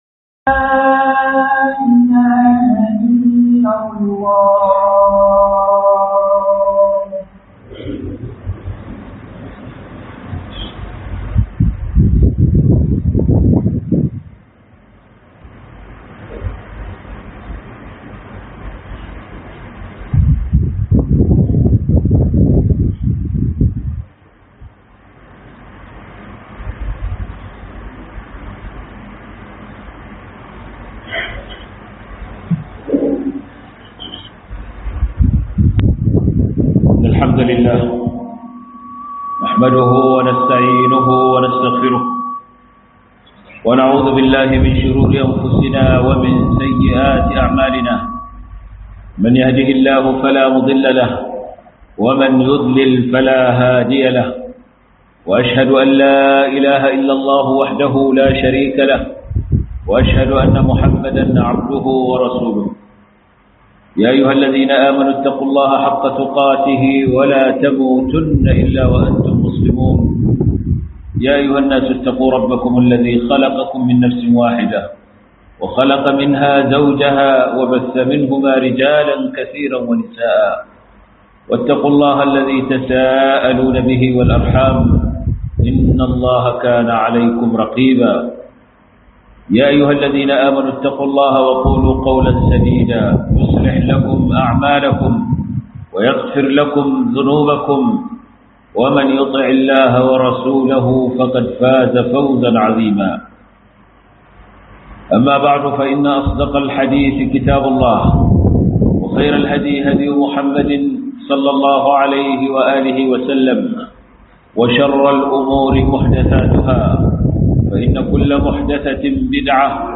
KOMAWA GA ALLAH DA TUBA A GARESHI 2025-11-07 - HUDUBA